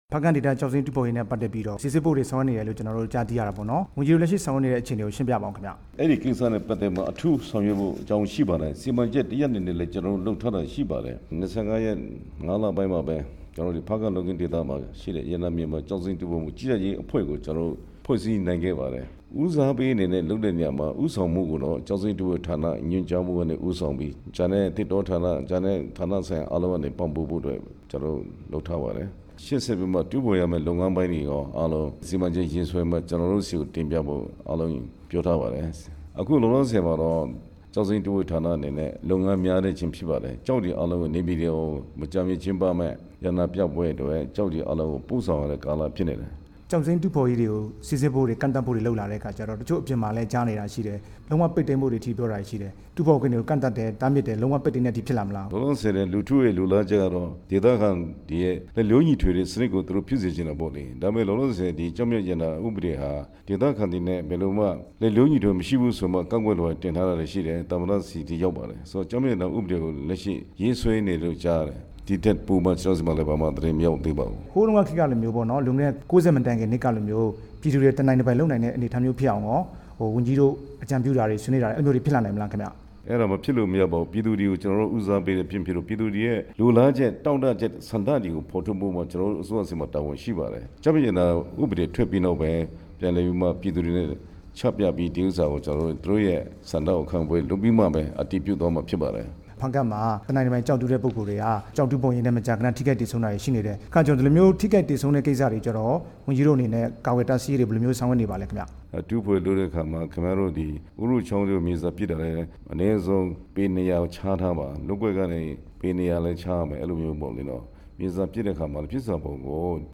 ဝန်ကြီး ဦးHလအောင် ကို မေးမြန်းချက်